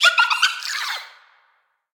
Sfx_creature_seamonkey_gimme_03.ogg